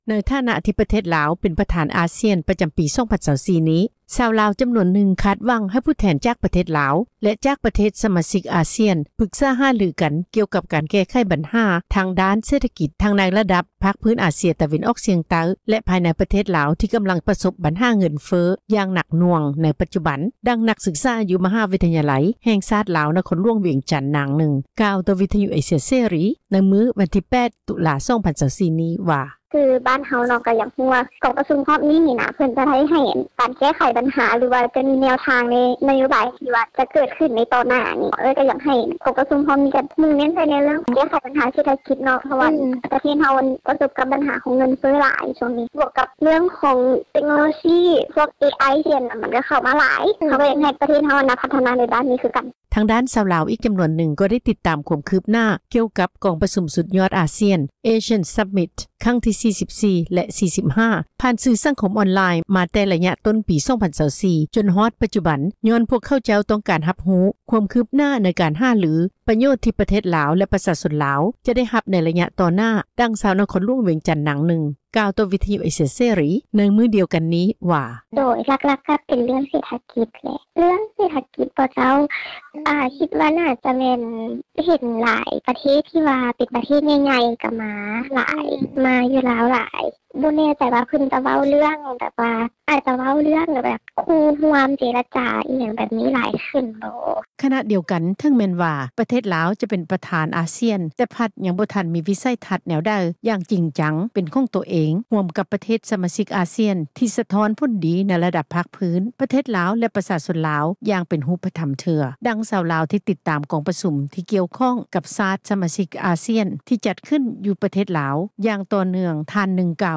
ໃນຖານະທີ່ປະເທດລາວ ເປັນປະທານອາຊ້ຽນປະຈໍາປີ 2024 ນີ້ ຊາວລາວຈໍານວນນຶ່ງ ຄາດຫວັງ ໃຫ້ຜູ້ແທນຈາກປະເທດລາວ ແລະ ຈາກປະເທດ ສະມາຊິກອາຊ້ຽນ ປືກສາຫາລືກັນ ກ່ຽວກັບການແກ້ໄຂ ບັນຫາທາງດ້ານເສດຖະກິດ ທັງໃນລະດັບພາກພື້ນເອຊຽນຕະເວັນອອກສຽງໃຕ້ ແລະ ພາຍໃນປະເທດລາວ ທີ່ກໍາລັງປະສົບບັນຫາເງິນເຟີ້ ຢ່າງໜັກຫນ່ວງໃນປັດຈຸບັນ. ດັ່ງນັກສືກສາ ຢູ່ມະຫາວິທະຍາໄລ ແຫ່ງຊາດລາວ ນະຄອນຫລວງວຽງຈັນ ນາງນຶ່ງ ກ່າວຕໍ່ວິທຍຸເອເຊັຽເສຣີ ໃນມື້ວັນທີ 8 ຕຸລາ 2024 ນີ້ວ່າ:
ທາງດ້ານຊາວລາວອີກຈໍານວນນຶ່ງ  ກໍໄດ້ຕິດຕາມຄວາມຄືບໜ້າ ກ່ຽວກັບກອງປະຊຸມ ສຸດຍອດຜູ້ນຳອາຊ້ຽນ (ASEAN Summits) ຄັ້ງທ 44-45 ຜ່ານສື່ສັງຄົມອອນລາຍນ໌ມາແຕ່ໄລຍະຕົ້ນປີ 2024 ຈົນມາຮອດປັດຈຸບັນ ຍ້ອນຕ້ອງການຮັບຮູ້ ຄວາມຄືບໜ້າ ໃນການຫາລື, ປະໂຫຍດທີ່ປະເທດລາວ ແລະ ປະຊາຊົນລາວ ຈະໄດ້ຮັບໃນໄລຍະຕໍ່ໜ້າ, ດັ່ງ ຊາວນະຄອນຫລວງວຽງຈັນ ນາງນຶ່ງ ກ່າວຕໍ່ວິທຍຸ ເອເຊັຽເສຣີ ໃນມື້ດຽວກັນນີ້ວ່າ: